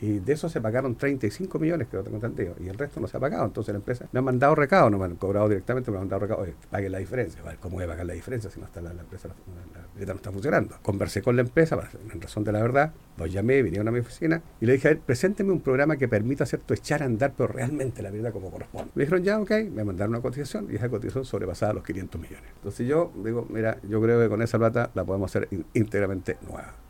En conversación con Radio Bío Bío, el alcalde Bertín recordó que, en febrero, la empresa solicitó alrededor de 500 millones de pesos para entregar la pileta funcionando, pese a un contrato por 150 millones de pesos que contó con un aumento presupuestario de otros 20 millones.